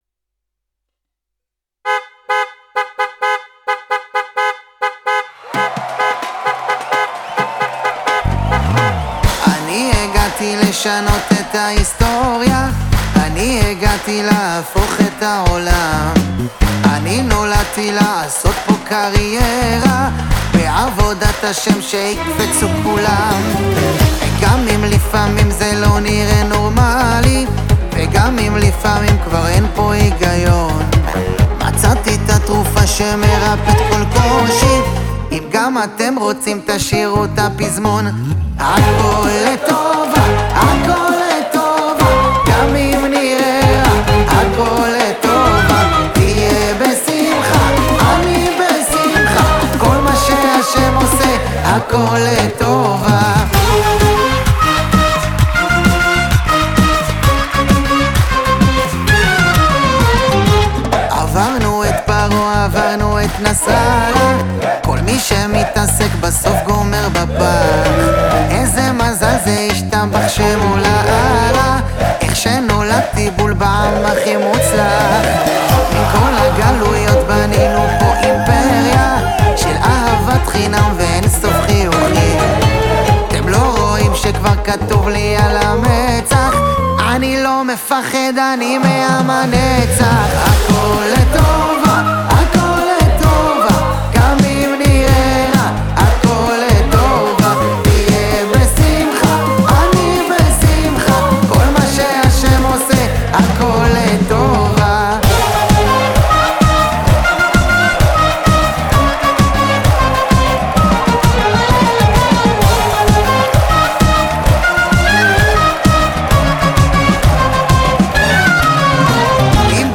השיר מצטרף למגמת שירי הפופ האמוני